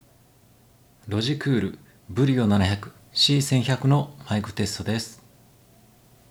内蔵マイク デュアル ビームフォーミング マイク
音を再生できますが、大きめの音量なので注意してください。
「ロジクール BRIO 700（C1100）」のマイク音質
Webカメラのマイク音質とは思えないほどクリアに感じます。
「周囲のノイズをAIがリアルタイムで解析・抑制」とありましたが、AI効果が本当にスゴイ。
部屋の反響音もかなり減ります。
さらに部屋の反響音がかなり抑えられているので、ワンランク以上の進化を感じます。